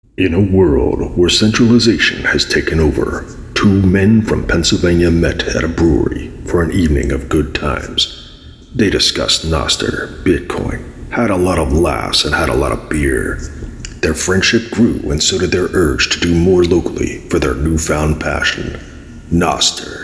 more cowbell!